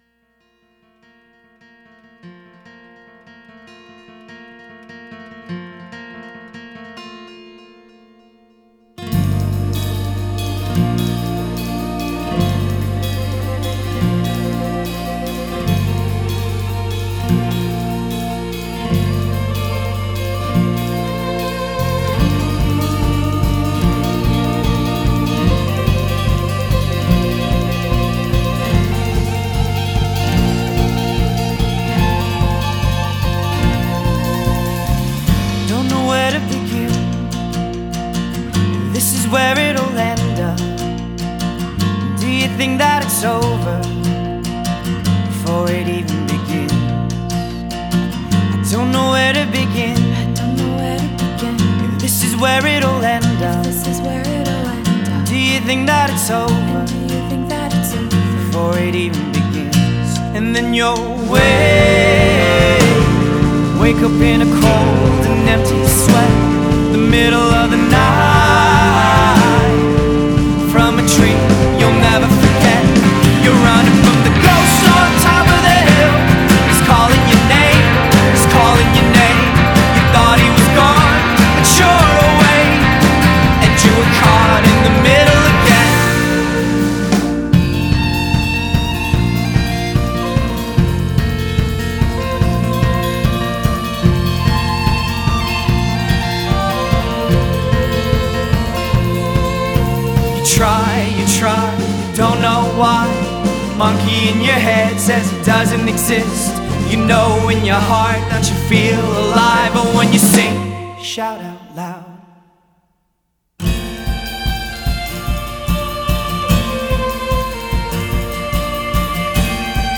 Indie Alternative rock Folk rock